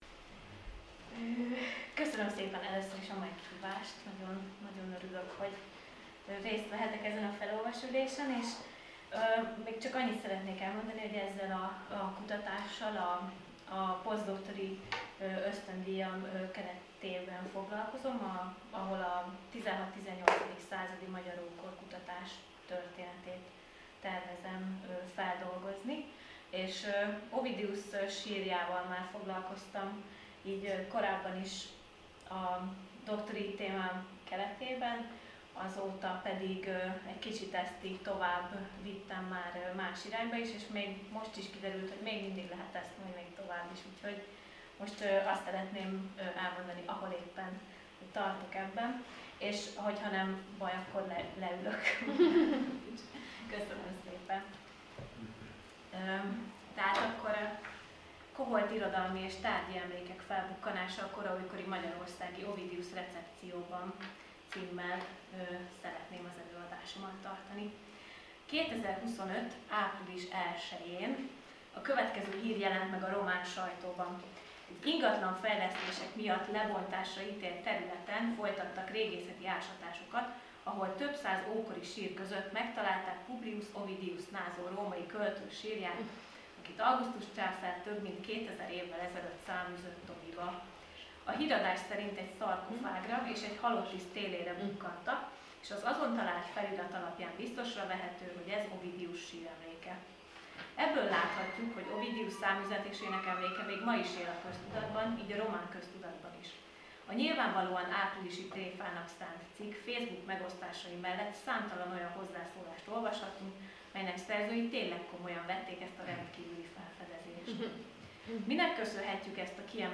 (lecturer)